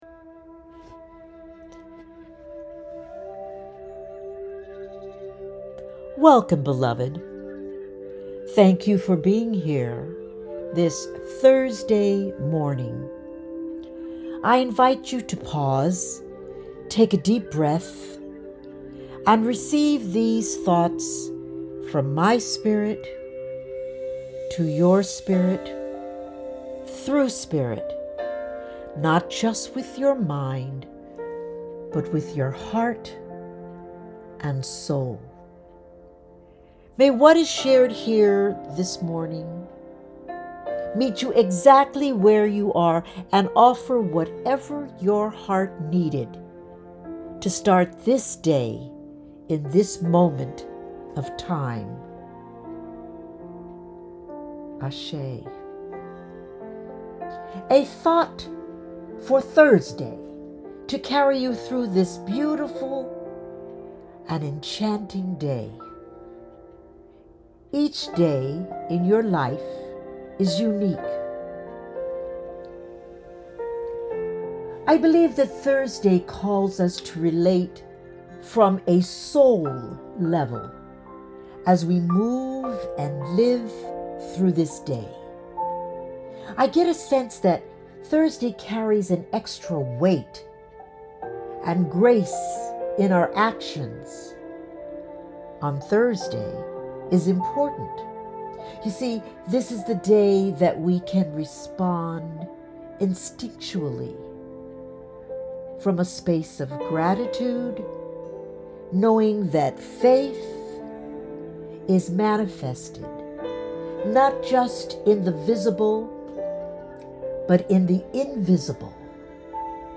Thank You Jim Brickman for your beautiful music that vibrates through this recording. This is a selection from his album – “Music to Quiet Your Mind and Sooth Your World”.